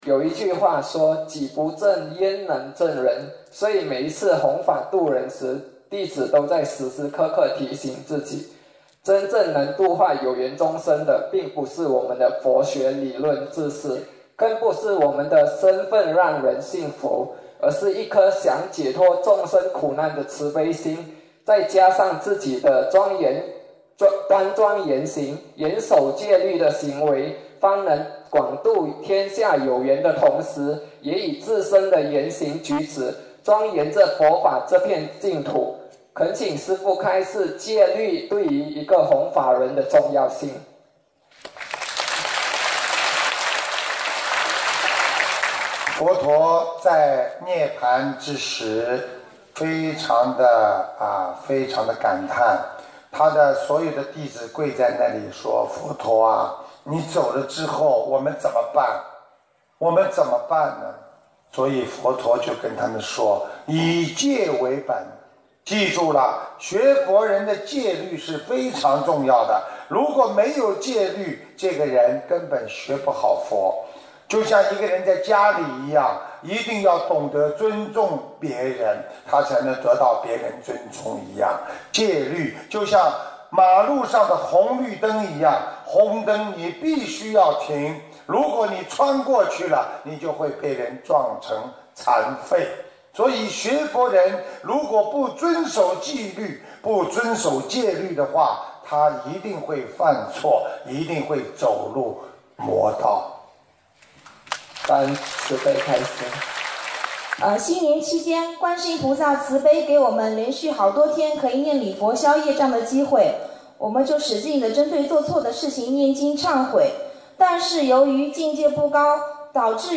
沙巴世界佛友见面会共修组提问150119